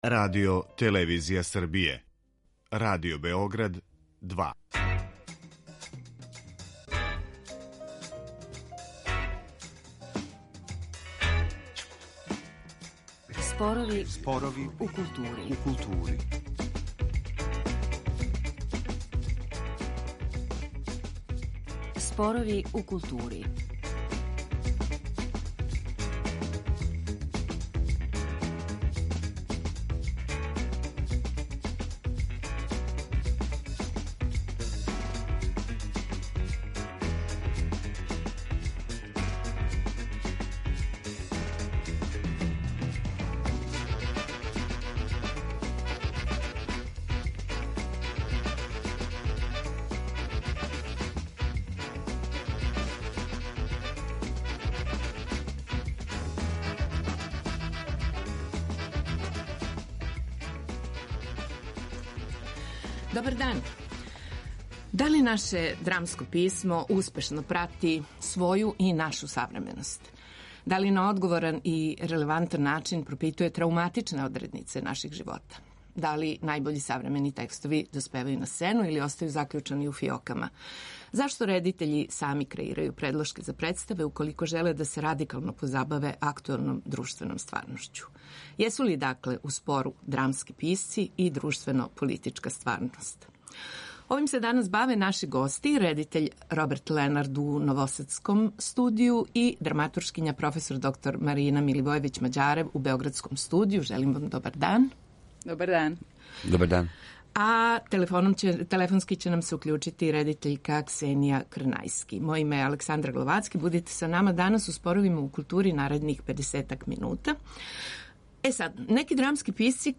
О овим питањима у студију разговарају